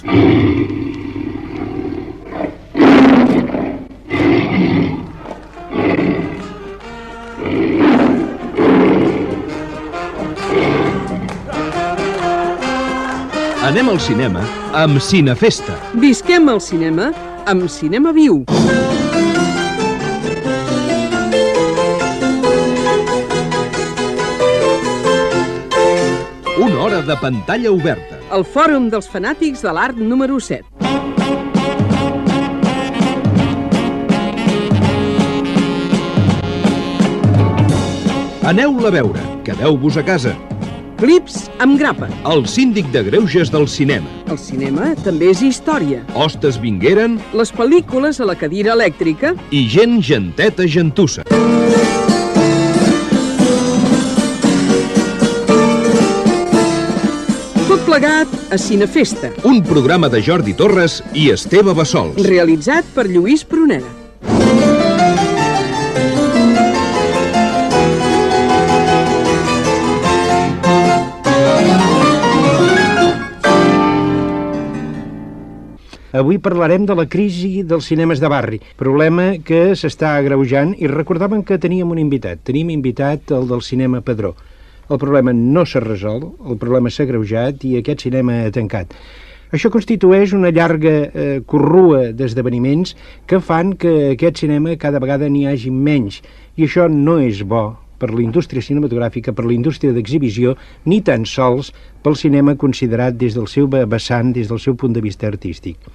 Careta amb les seccions del programa, presentació i informació que ha tancat el cinema Padró de Barcelona.